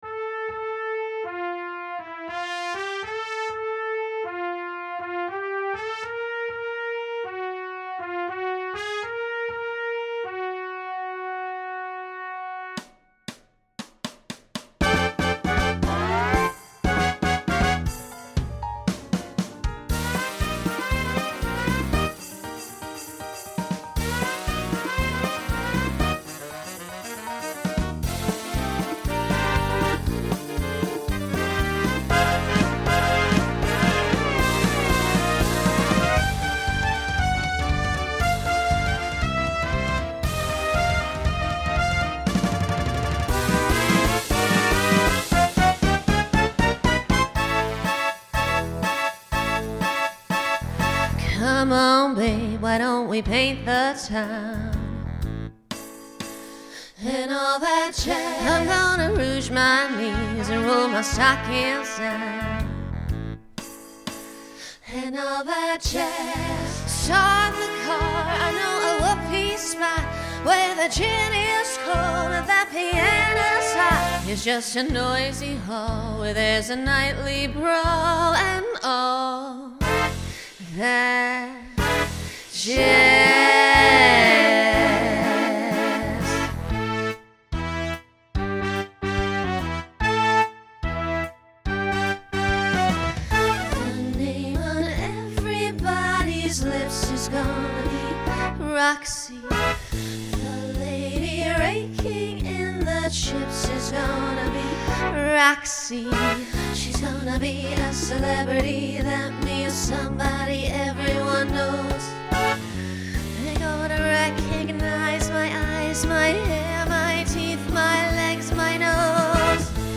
Genre Broadway/Film Instrumental combo
Show Function 2nd Number Voicing SSA